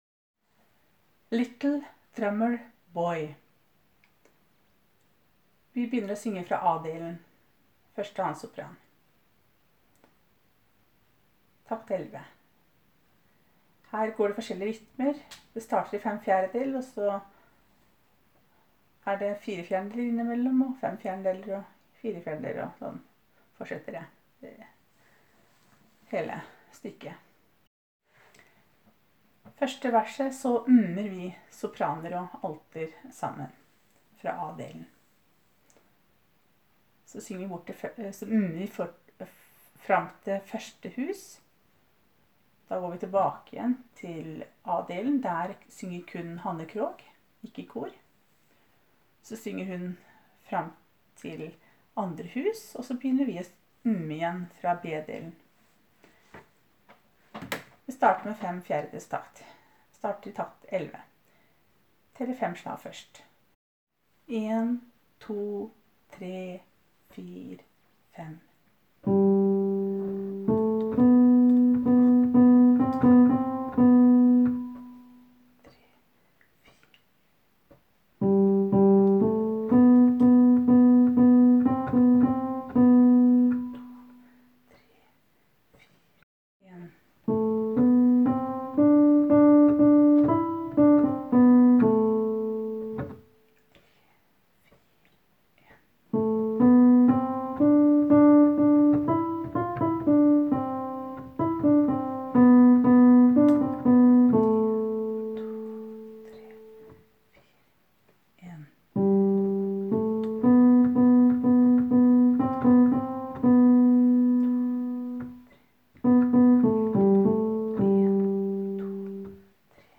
Jul 2017 Sopraner (begge konserter)
Little-Drummer-Boy-1-og-2-Sopraner.m4a